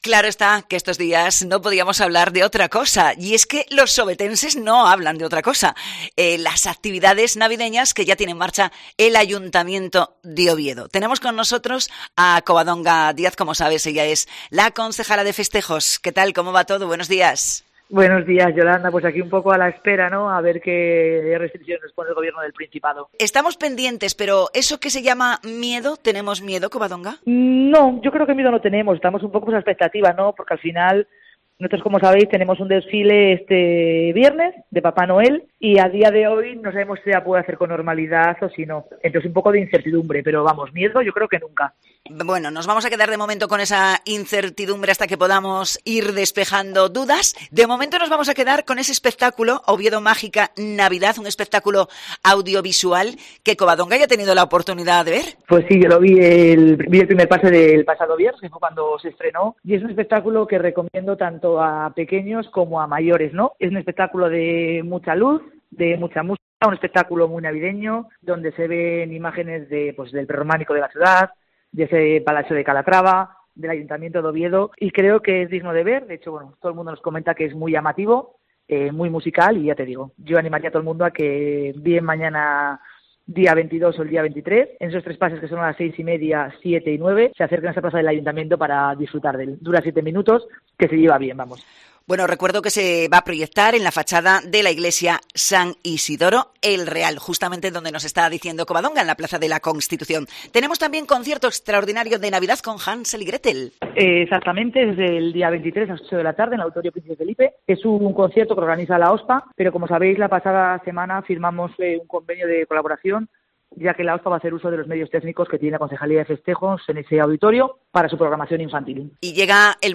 Entrevista con Covadonga Díaz, Concejala de Festejos del Ayuntamiento de Oviedo